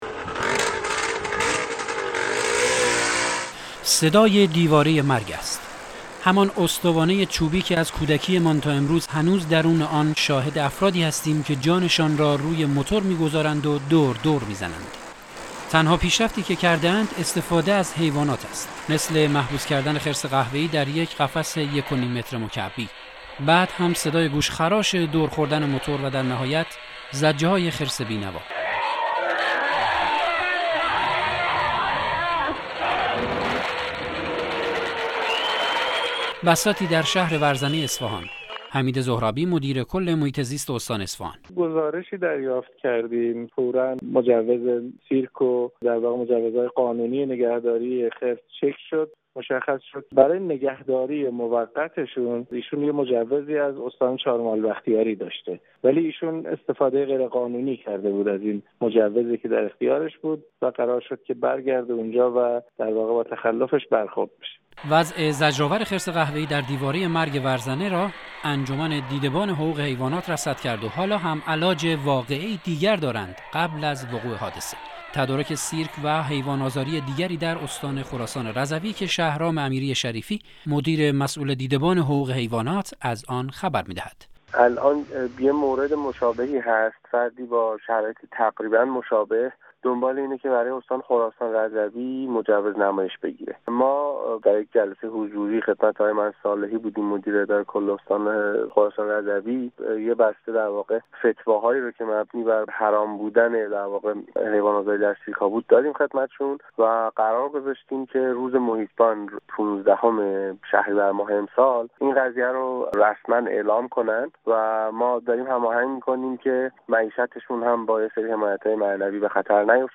بعدهم صحنه و صدای گوشخراش دورخوردن موتور و درنهایت زجه های خرس بینوا.